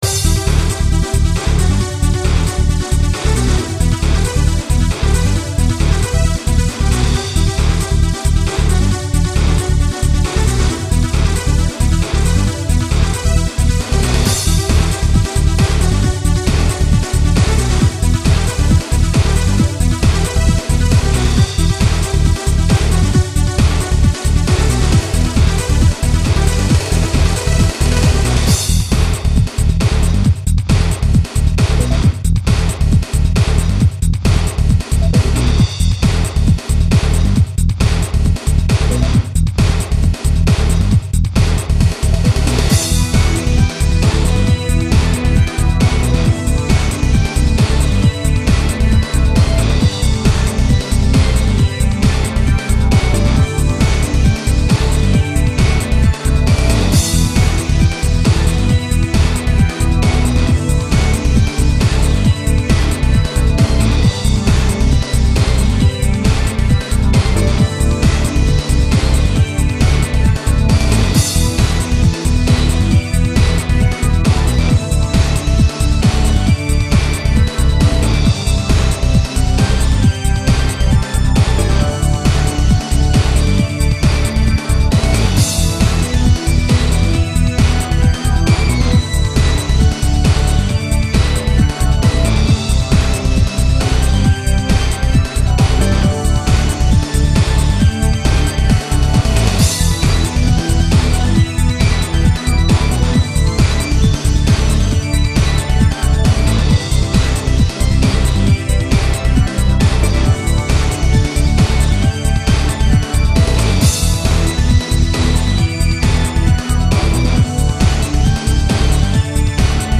前回とはうって変わって、テクノやアンビエント、更にはジャズ崩れ（？）まで。
あえてオリエンタル等の民族調を使わずに挑んだ、いつもと違うUGSサウンドです。